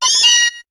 Cri de Miaouss dans Pokémon HOME.